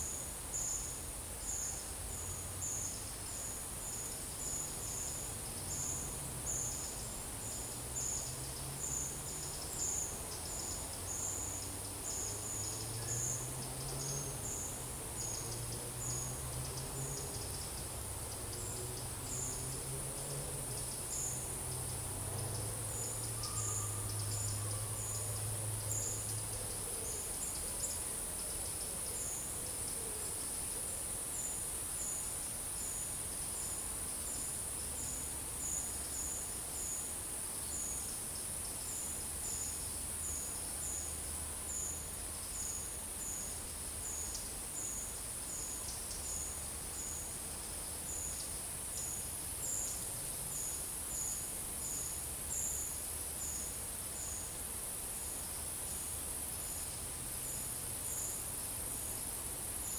Monitor PAM
Certhia familiaris
Troglodytes troglodytes
Certhia brachydactyla
Lophophanes cristatus